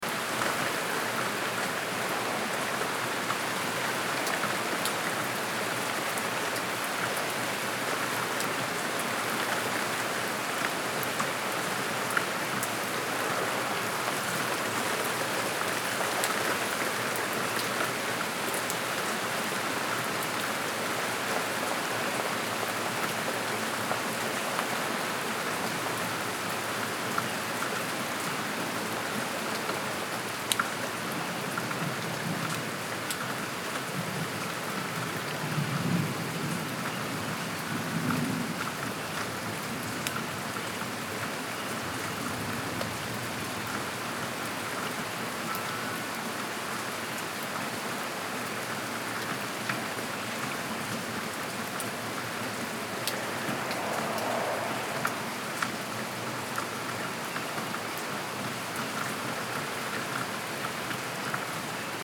White noise online for kids - Raindrops
• Category: Sleep
• Quality: High